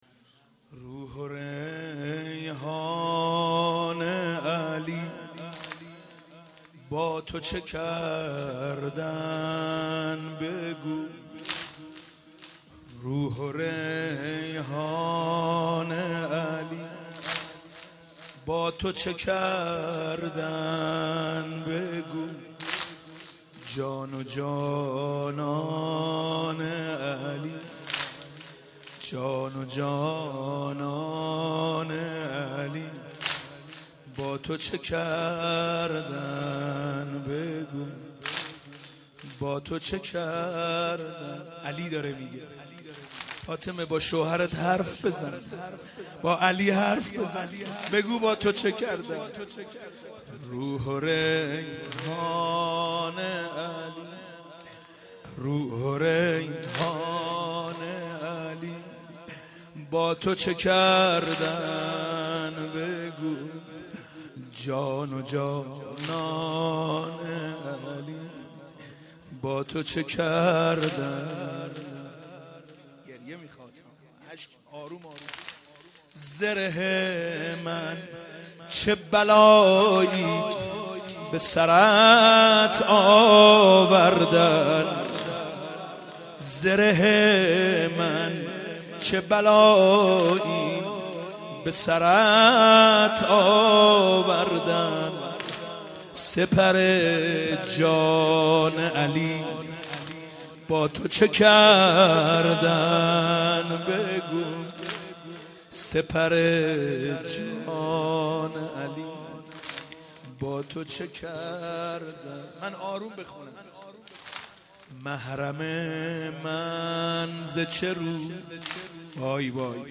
مراسم شب سوم فاطمیه ۱۳۹7
مداحی